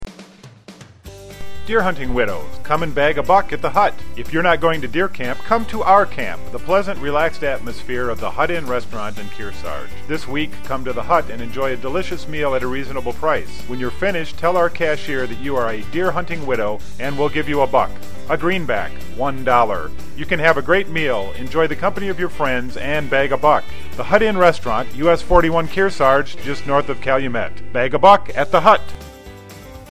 our new radio spot.